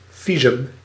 Ääntäminen
Tuntematon aksentti: IPA: /'vis.ta/